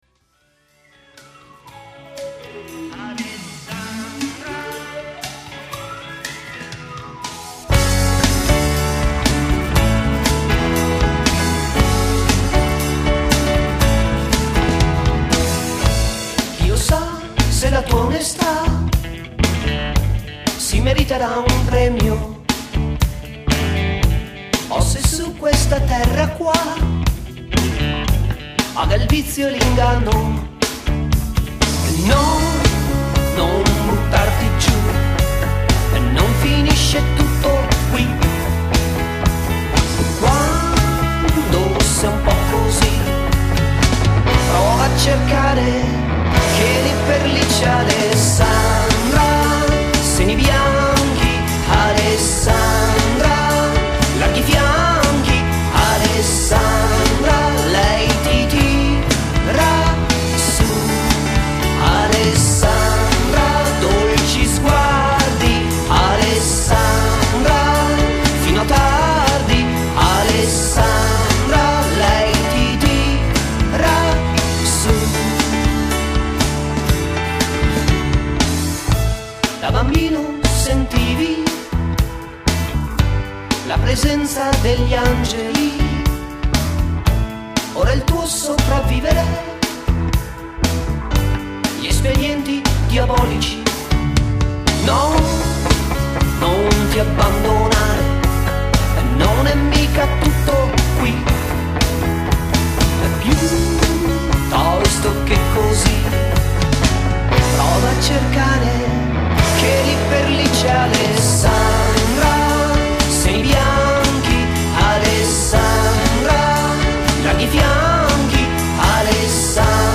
Luogo esecuzioneELFOSTUDIO DI TAVERNAGO (PC)
GenerePop